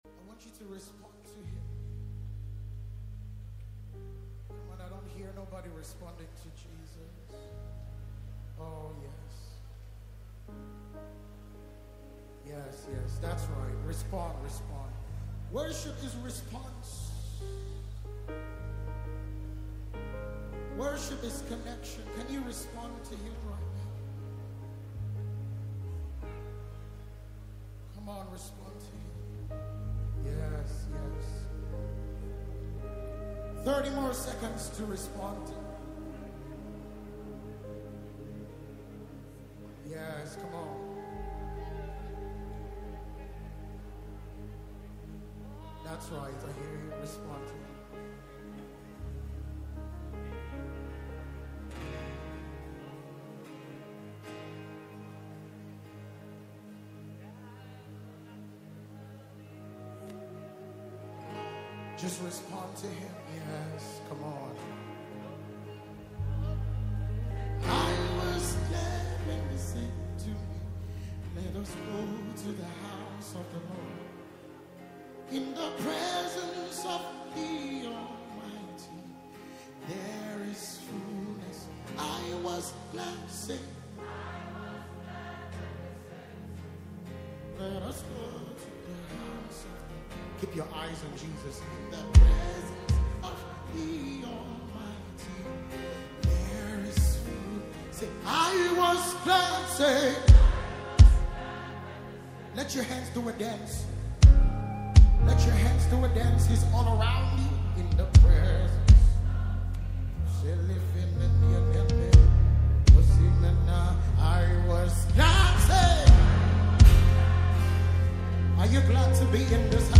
Chant Music
Worship